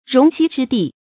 容膝之地 róng xī zhī dì 成语解释 形容居室的狭窄 成语出处 晋·陶渊明《归去来兮辞》：“审容膝之易安。”